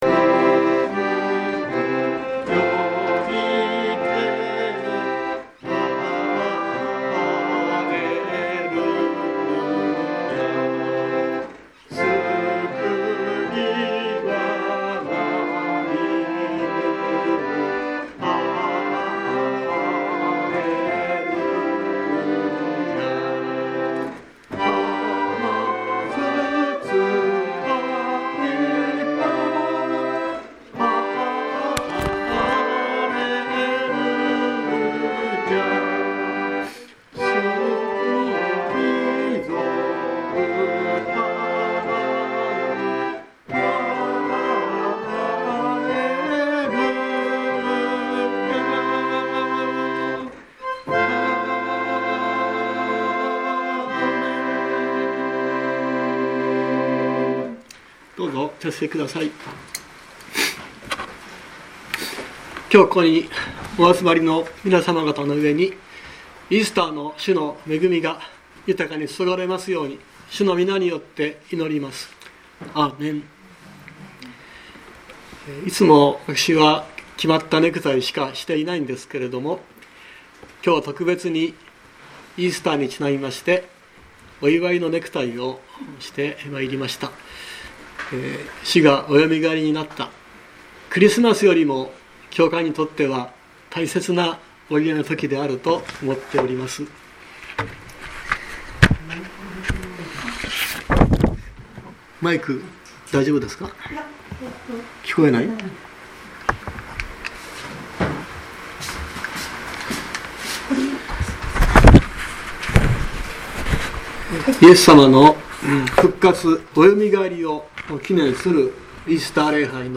説教アーカイブ。
日曜朝の礼拝
イースター礼拝説教